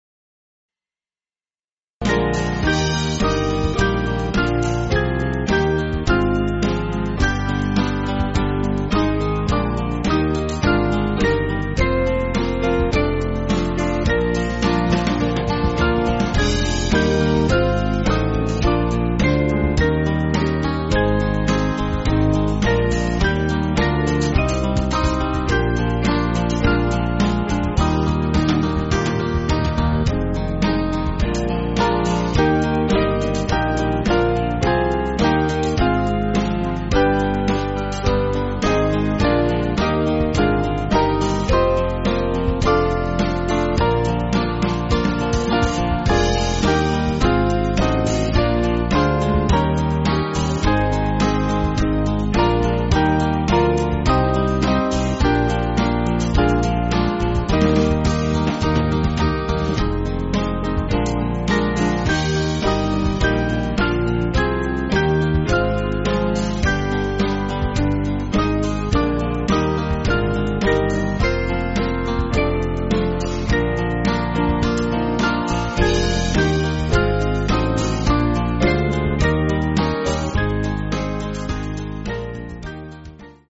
Small Band
(CM)   5/Eb